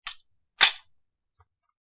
Standard Bullet Load Into Pistol, X3